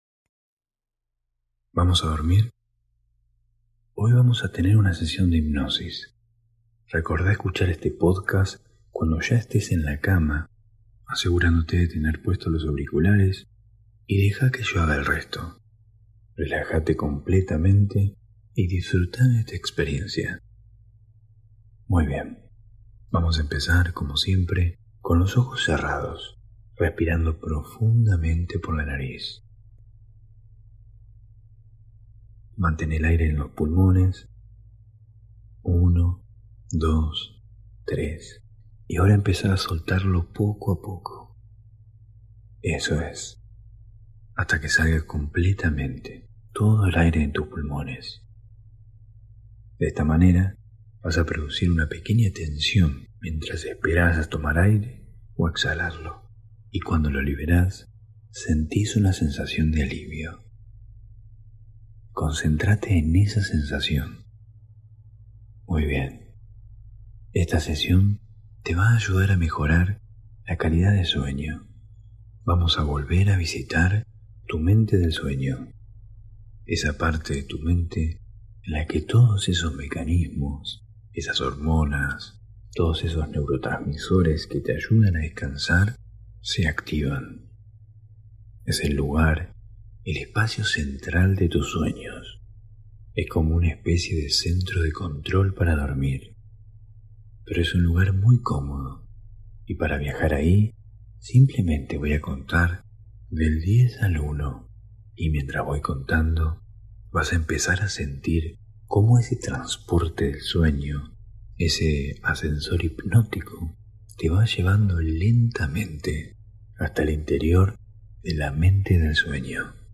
Hipnosis para dormir 4
Sesión de hipnosis para dormir y mejorar la calidad de sueño. Hosted on Acast.